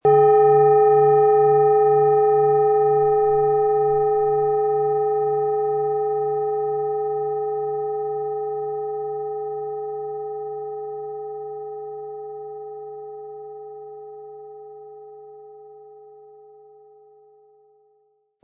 Energie entfachen mit Mars, Ø 16,5 cm Klangschale im Sound-Spirit Shop | Seit 1993
Planetenton 1
Diese tibetische Planetenschale Mars ist von Hand gearbeitet.
MaterialBronze